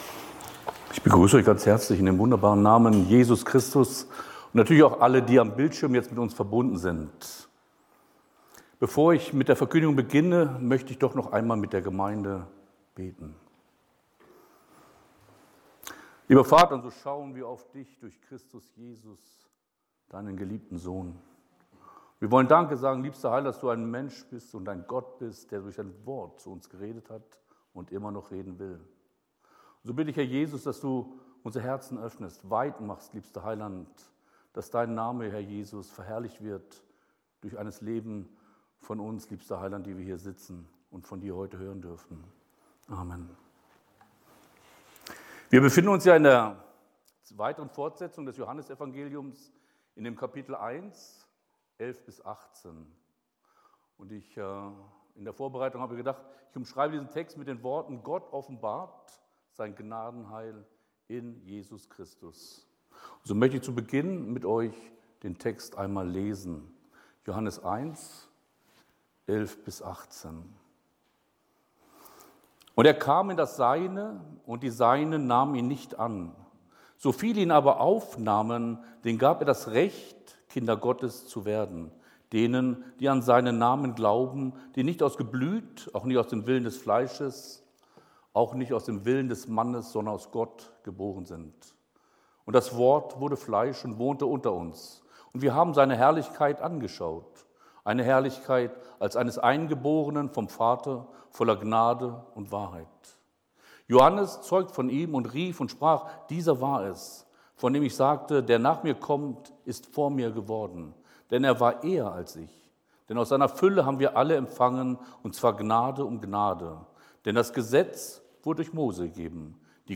Heute predigte